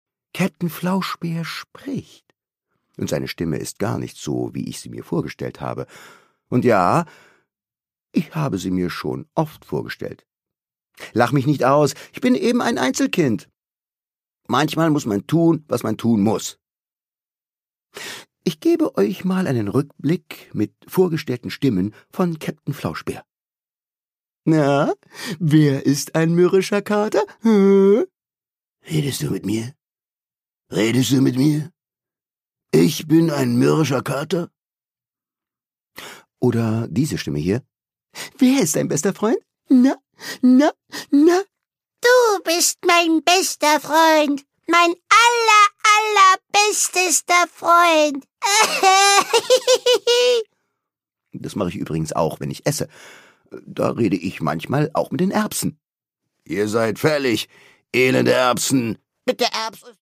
Produkttyp: Hörbuch-Download
Gelesen von: Thomas Nicolai